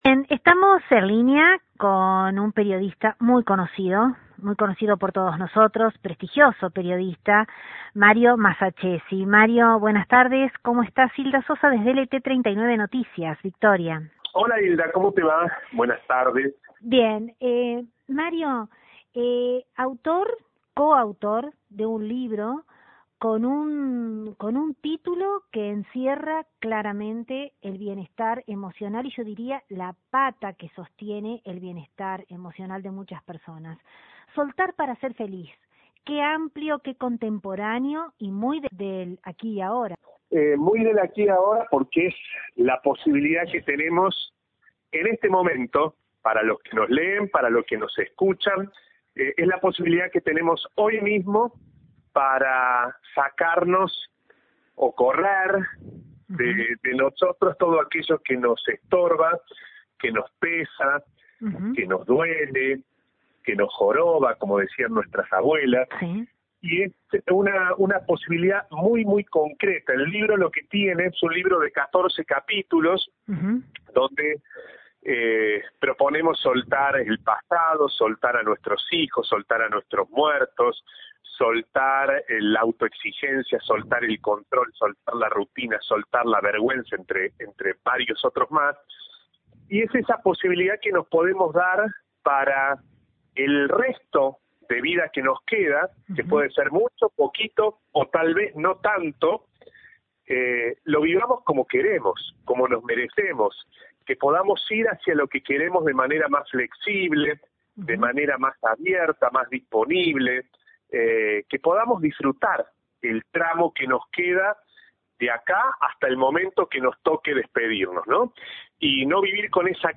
Desde LT39 NOTICIAS, dialogamos con el prestigioso comunicador, Mario Massaccessi; quien de manera enfática expresó, “cuando vienen a nuestros talleres, no compramos la historia que nos traen, porque muchas veces, hay más de fantasía que de realidad…hay que correrse para tener otra mirada”.